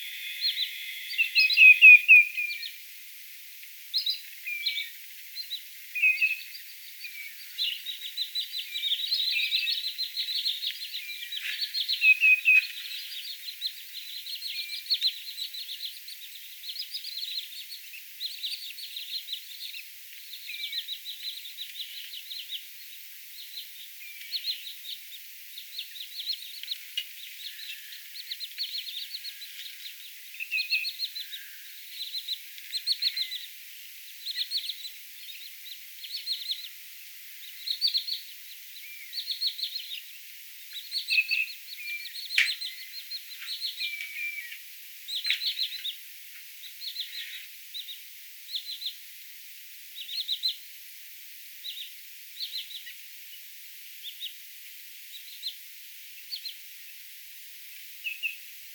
lirojen ja valkoviklojen ääniä rannalla
lirojen_ja_valkoviklojen_aania_rannalla.mp3